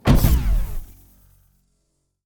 More SFX
Stomp2.wav